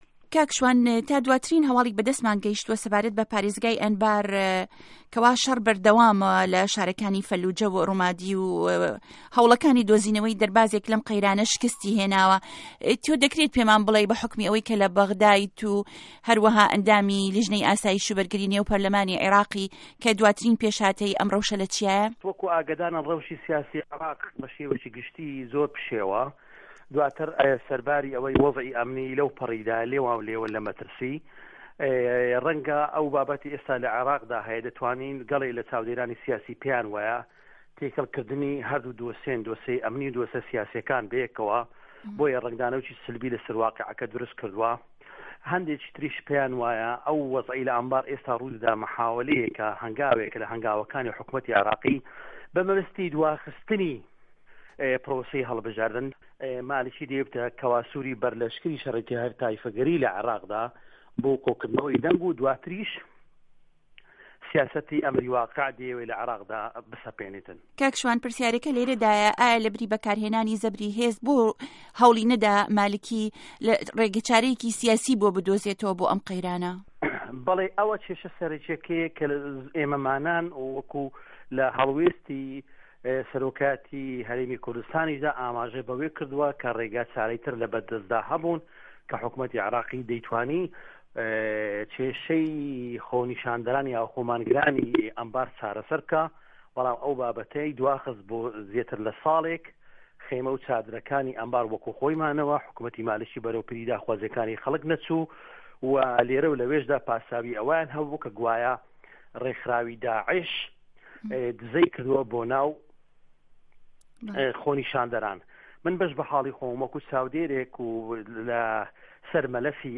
گفتوگۆ ڵه‌گه‌ڵ شوان محه‌مه‌د 8ی 1ی ساڵی 2014